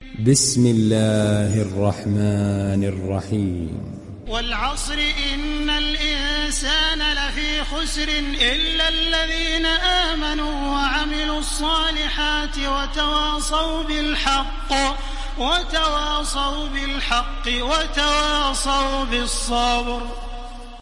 دانلود سوره العصر تراويح الحرم المكي 1430